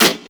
snr_28.wav